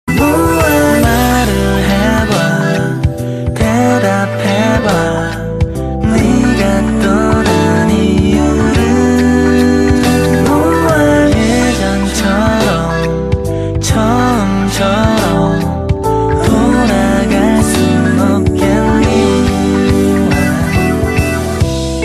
日韩歌曲
Romantico